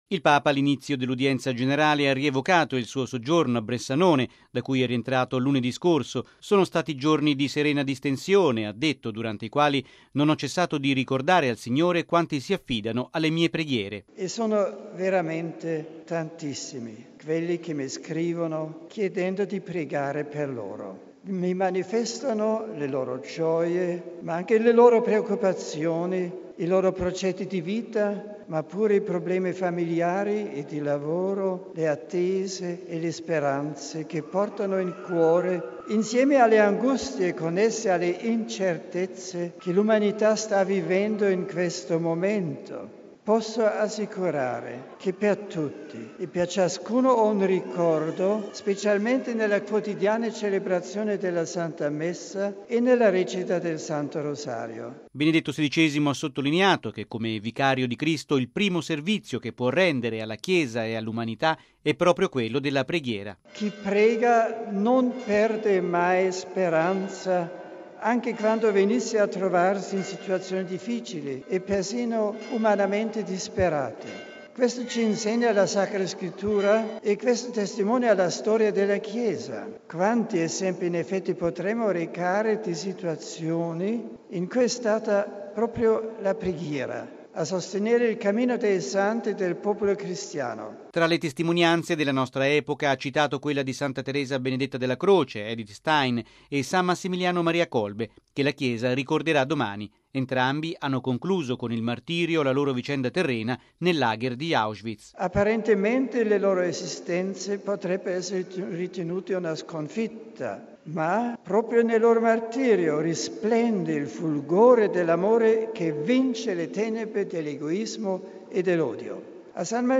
◊   Il Papa invoca il dono della pace nel mondo: lo ha fatto riprendendo questa mattina a Castel Gandolfo l’appuntamento tradizionale dell’udienza generale del mercoledì, sospeso dopo il 2 luglio per il viaggio in Australia e il soggiorno a Bressanone.
(applausi)